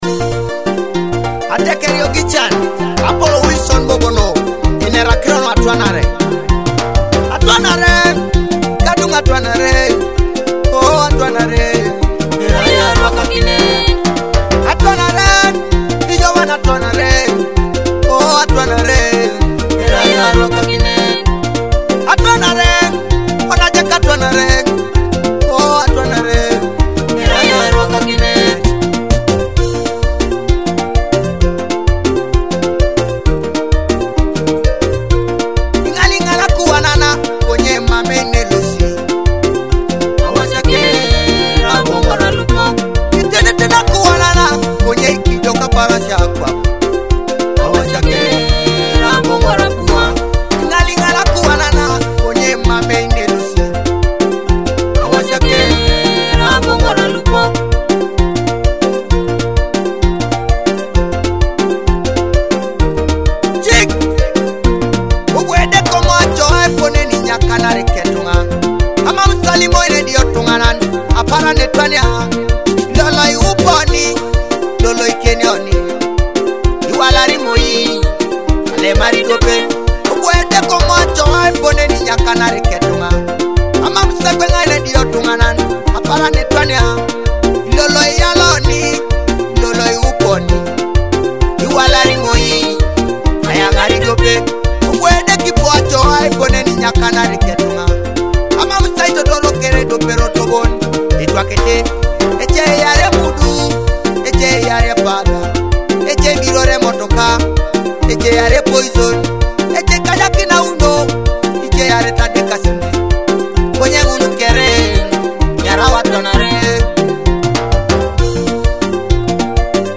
all wrapped in soulful Teso sounds